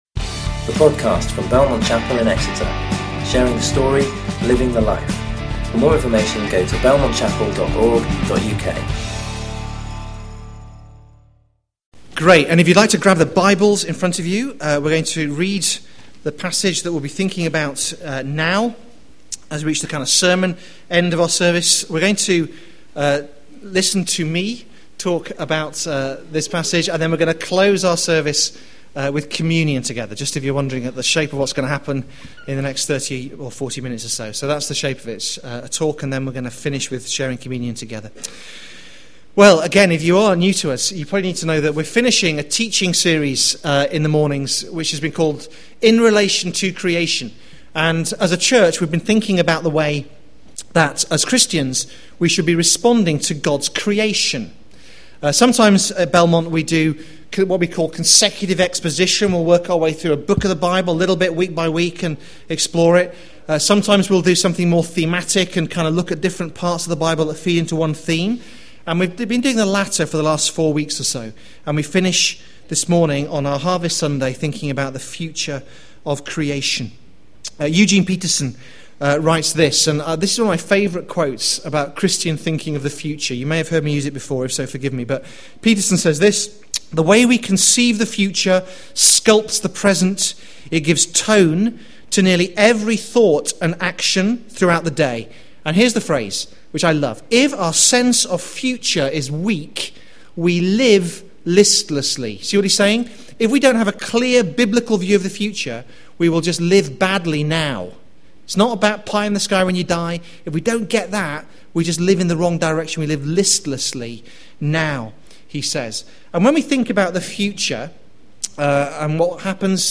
You can listen to or download sermons from Belmont Chapel.